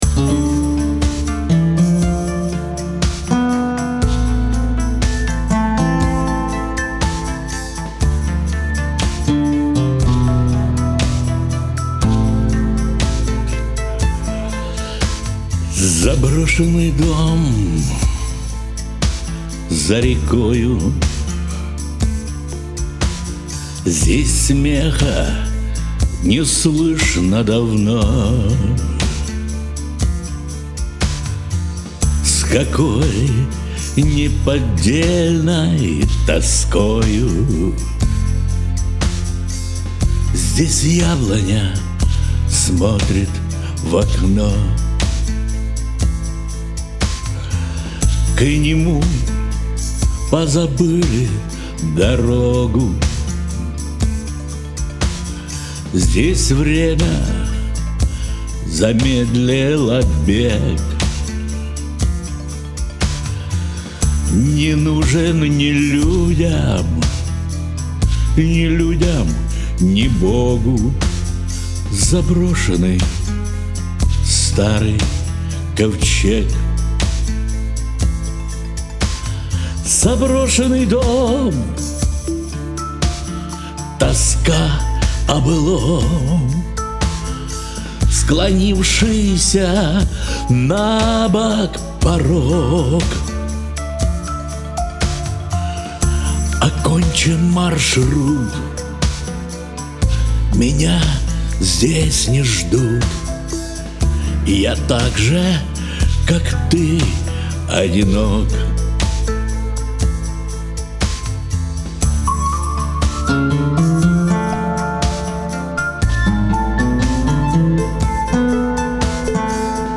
Красивые стихи, грустная песня...